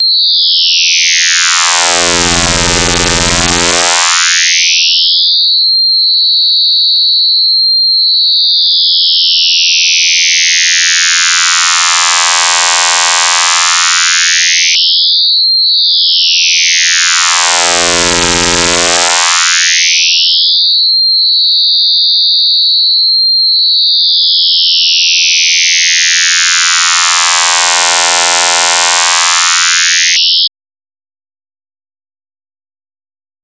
Звук отпугивающий голубей